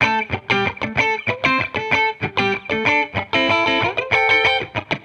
Index of /musicradar/sampled-funk-soul-samples/95bpm/Guitar
SSF_TeleGuitarProc2_95A.wav